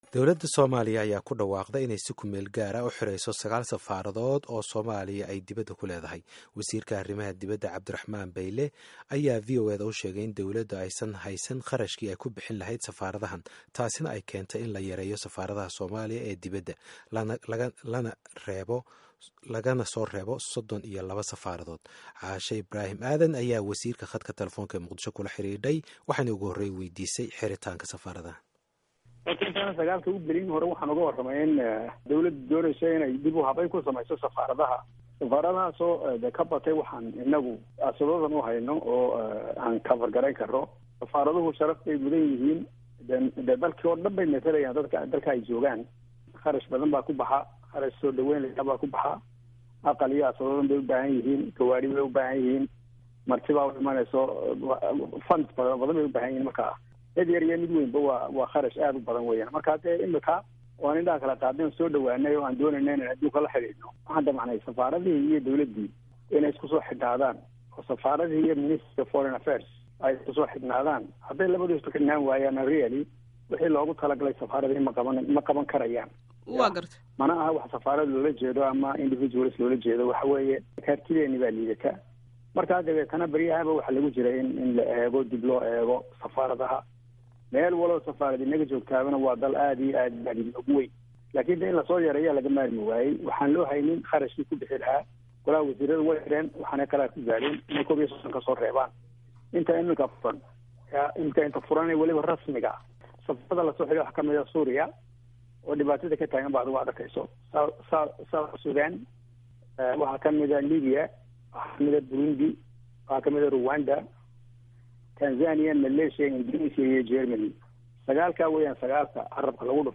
Dhegayso: Waraysi VOA la yeelatay wasiirka arrimaha dibadda ee Somalia